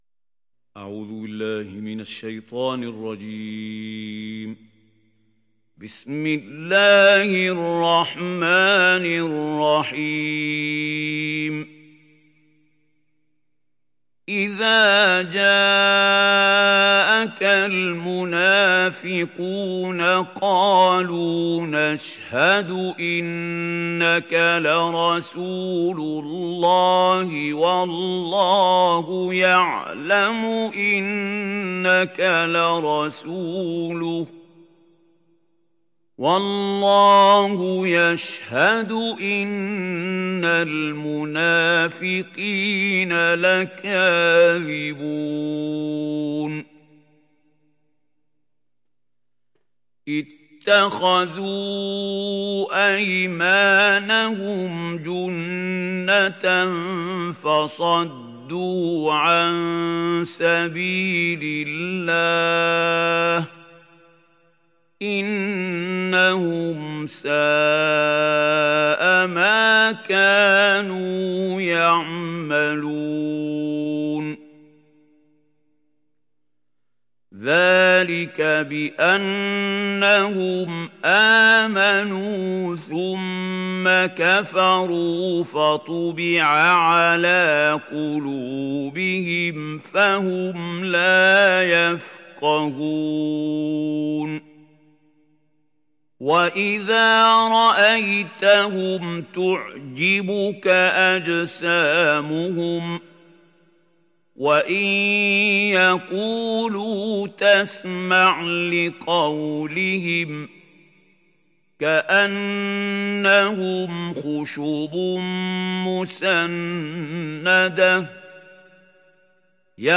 Sourate Al Munafiqun mp3 Télécharger Mahmoud Khalil Al Hussary (Riwayat Hafs)